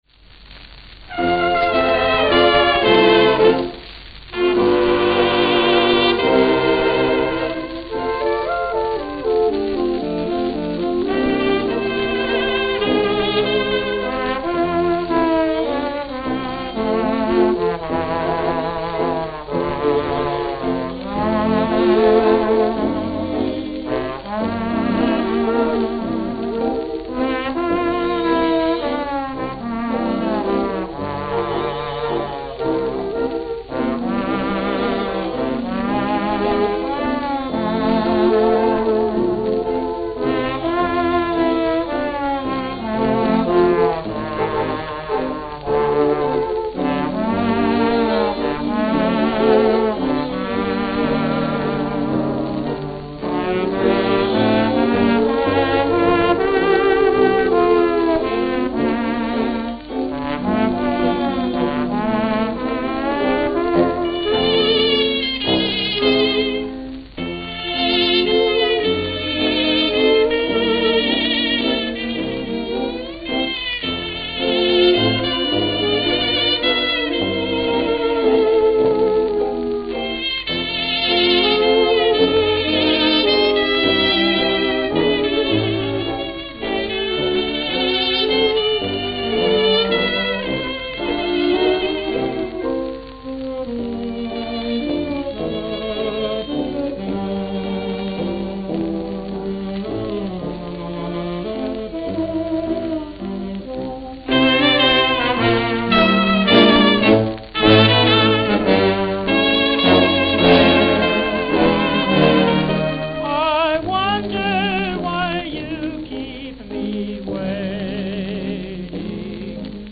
Chicago, Illinois Chicago, Illinois
Note: Worn.